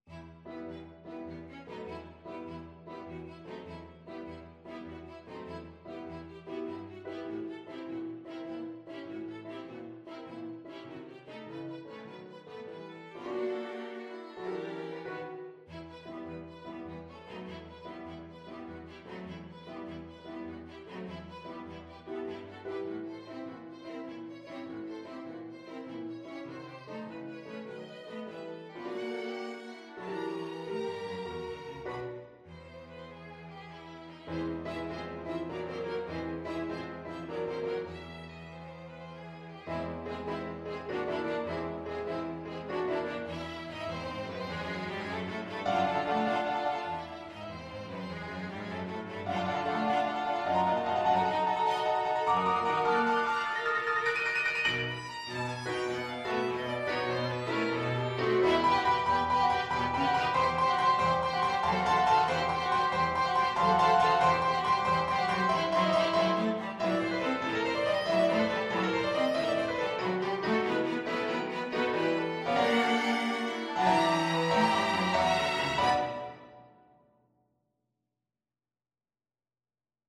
Violin 1Violin 2ViolaCelloPiano
3/8 (View more 3/8 Music)
Classical (View more Classical Piano Quintet Music)